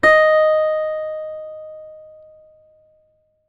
ZITHER D#3.wav